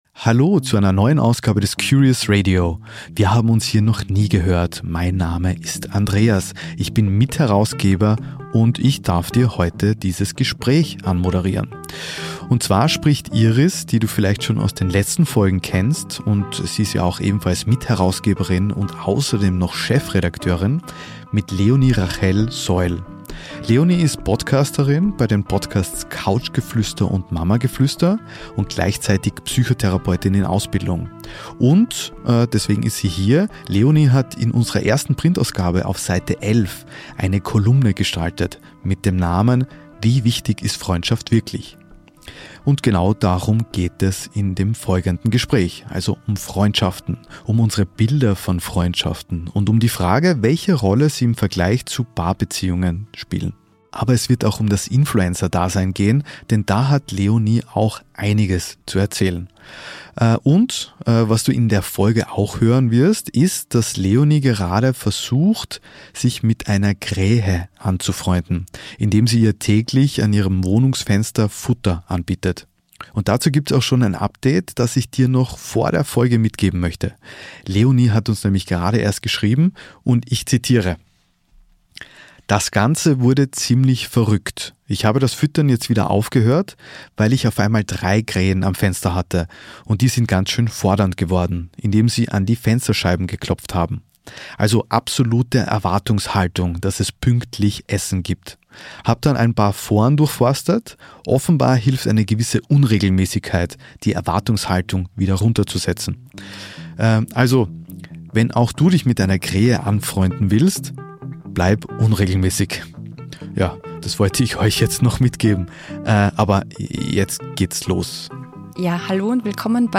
Jetzt haben wir sie ins Studio eingeladen, um über Freundschaften zu reden. Dabei sind wir aber ziemlich abgeschweift.